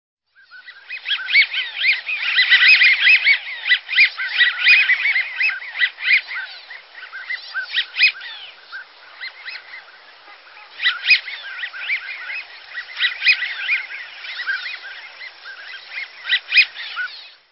Bird Sound
High squeaky whistle, resembling squeaky toy. Female quacks.
AmericanWigeon.mp3